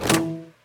ArrowCrossBowShot-003.wav